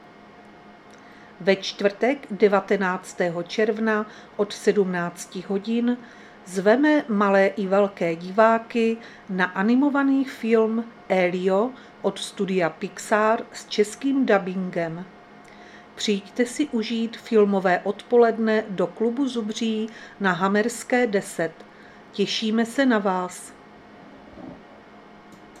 Záznam hlášení místního rozhlasu 18.6.2025
Zařazení: Rozhlas